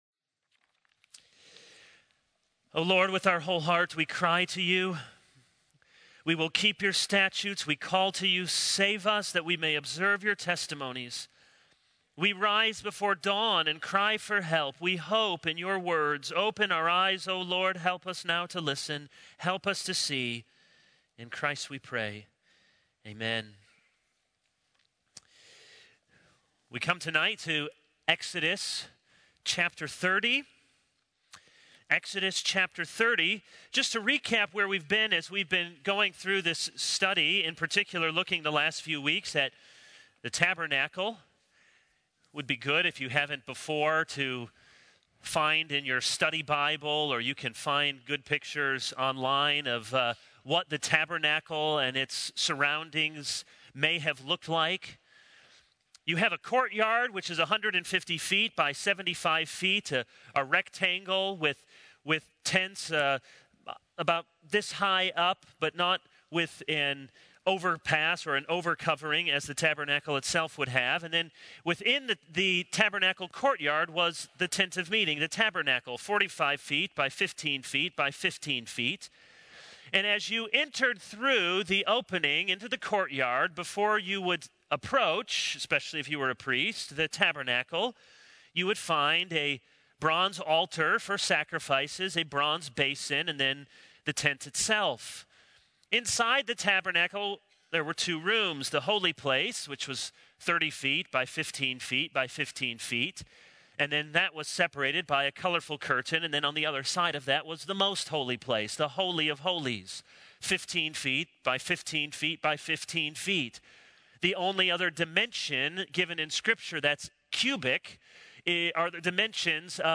This is a sermon on Exodus 30.